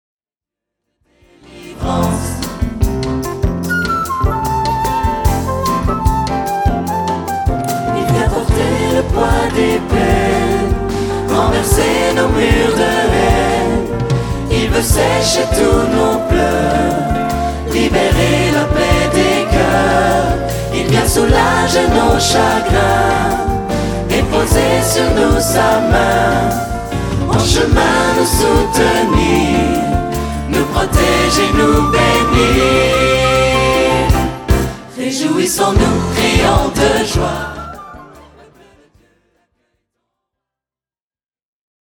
Album de louange en LIVE
Une chorale, des solistes et des musiciens de talent